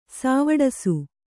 ♪ sāvaḍasu